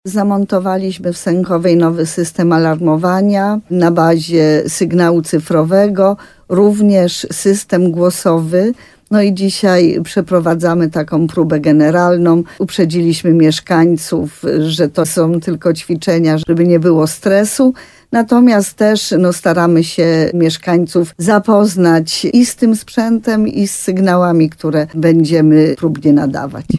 Przeprowadzane są testy nowego systemu ostrzegania ludności – informuje wójt Małgorzata Małuch, w programie Słowo za Słowo na antenie RDN Nowy Sącz.